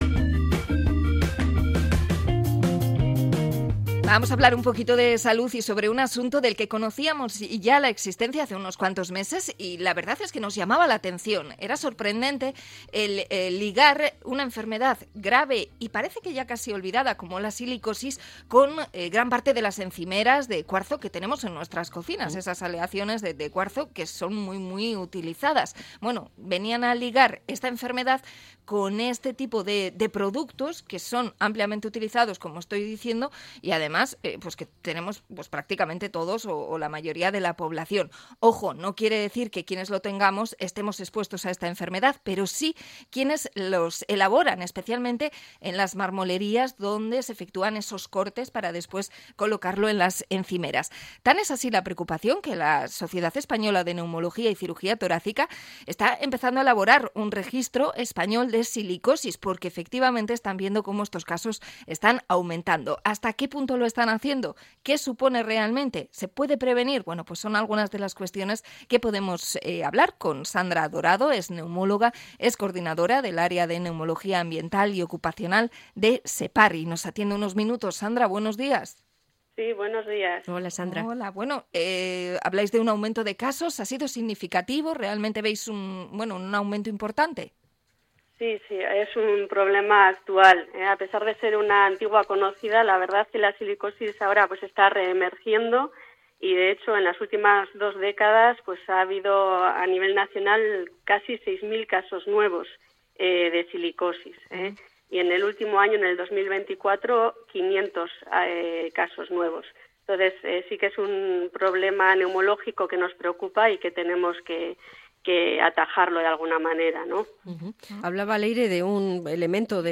Entrevista a neumóloga sobre la silicosis